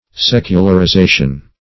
Secularization \Sec`u*lar*i*za"tion\, n. [Cf. F.